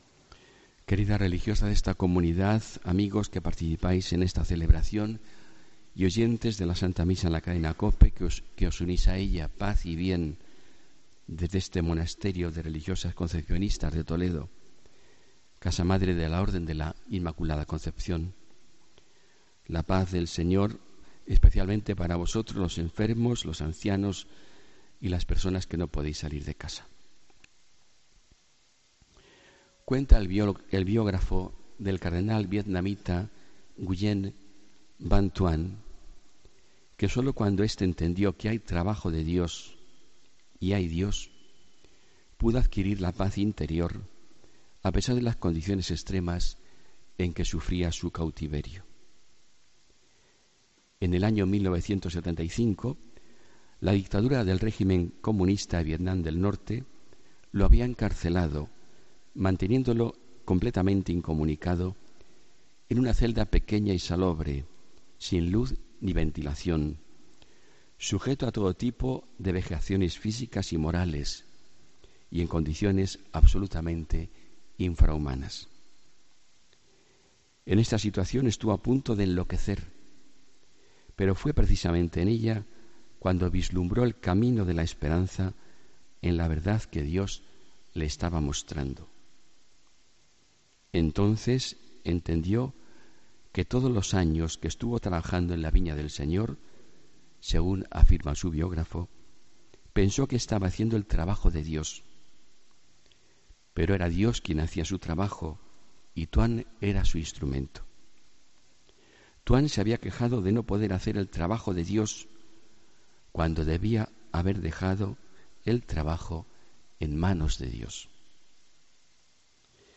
Homilía del domingo 23 de julio de 2017